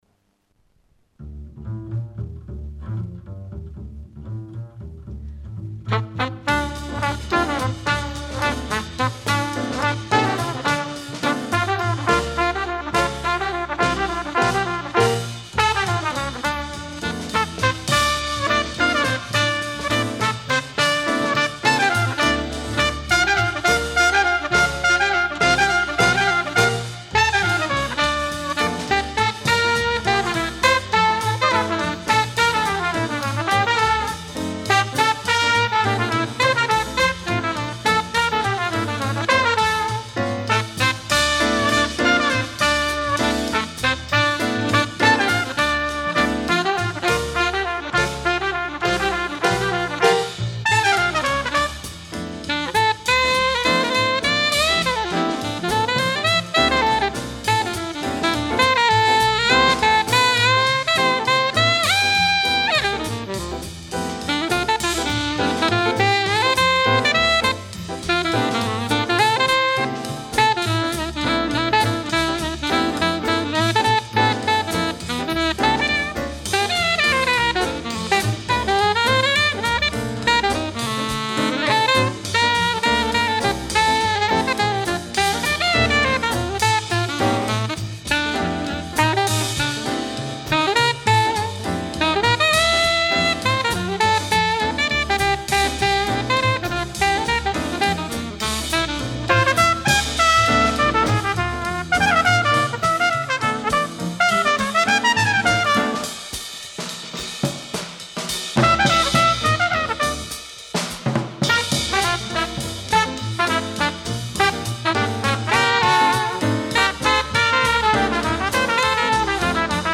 Новые поступления СТАРОГО РАДИО. Инструментальная музыка и песни советских композиторов (ч. 6-я).